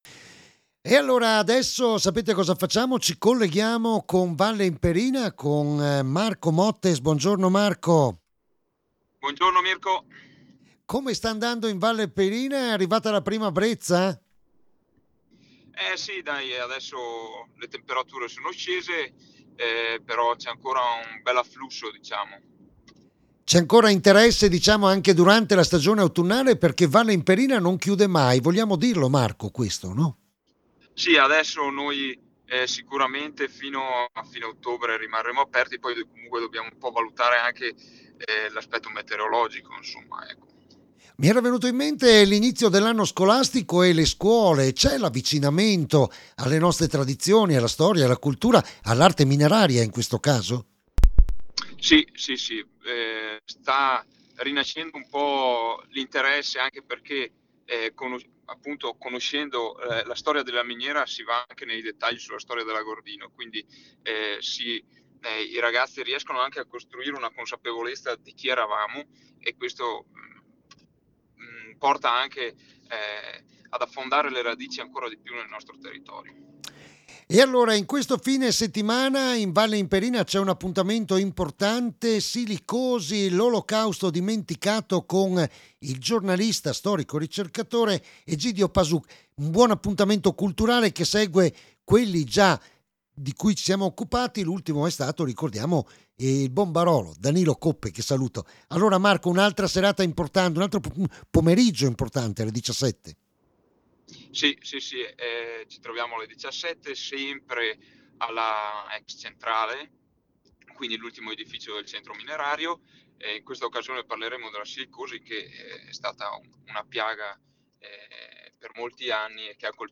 ai MICROFONI DI RADIOPIU DAL SITO DI VALLE IMPERINA